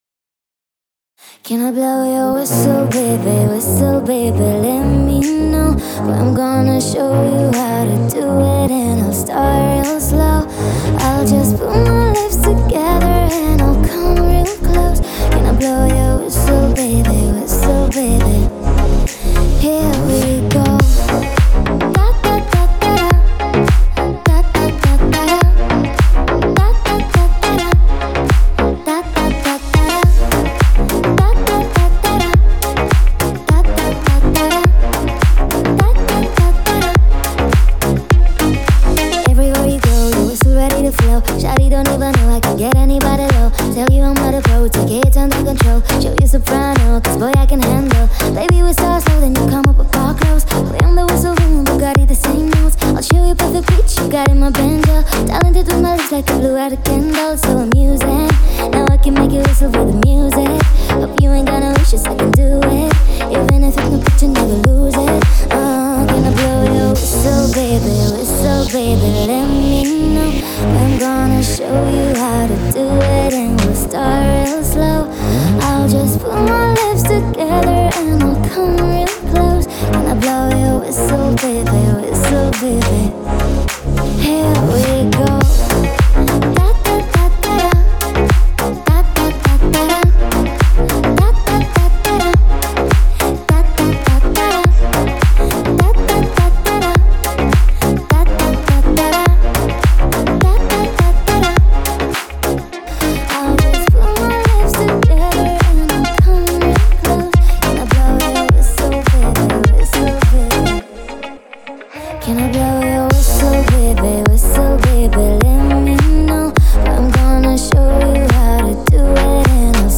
это яркая и зажигательная композиция в жанре поп